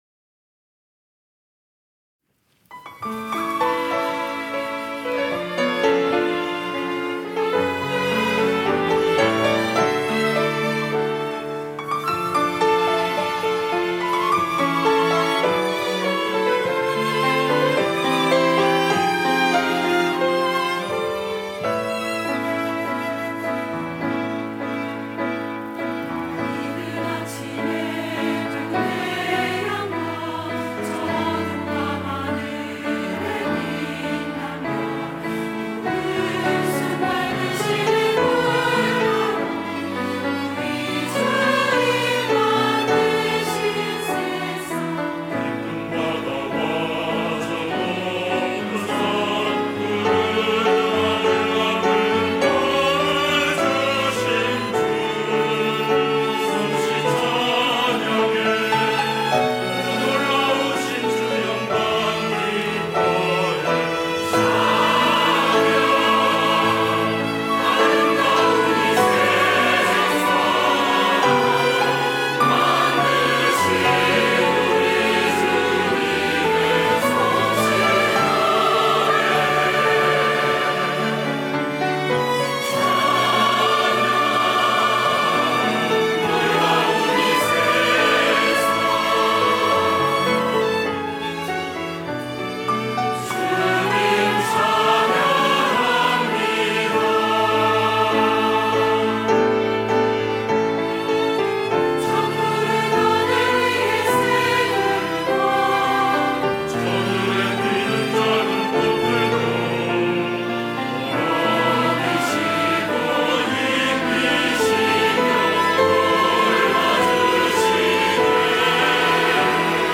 호산나(주일3부) - 주님 만드신 세상
찬양대